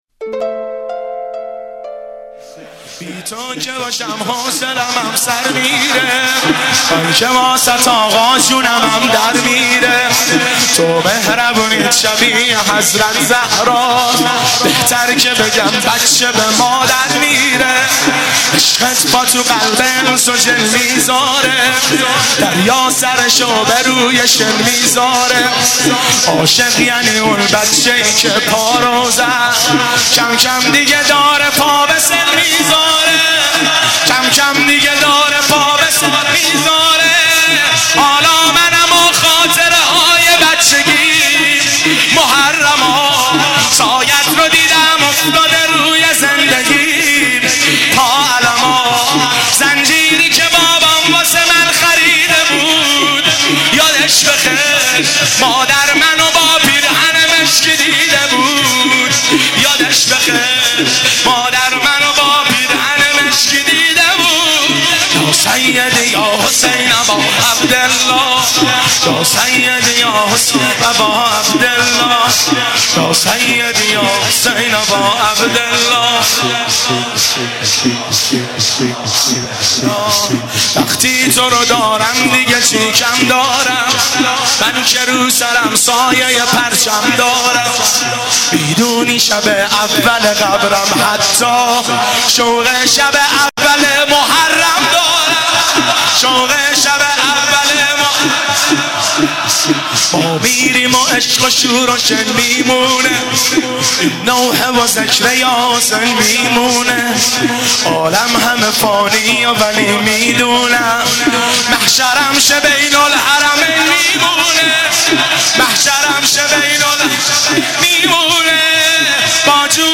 شب سوم رمضان 95
واحد، زمینه